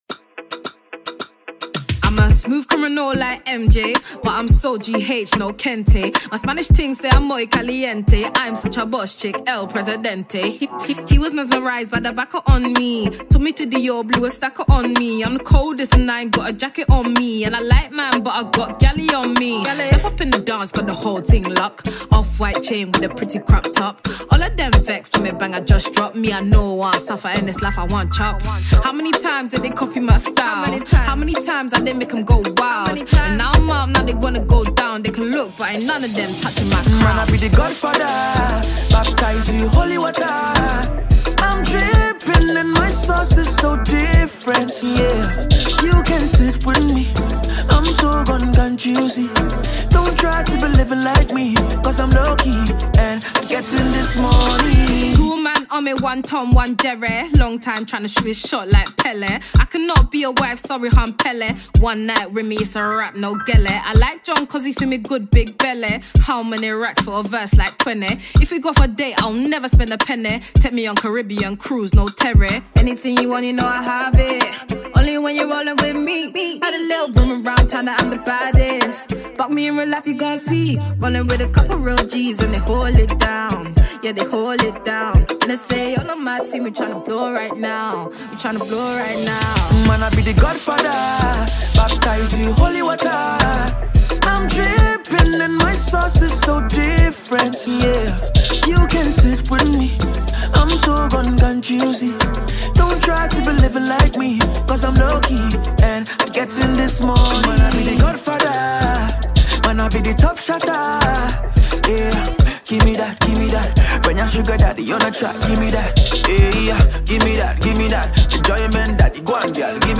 Ghana Music Music
Ghanaian UK-Based female rapper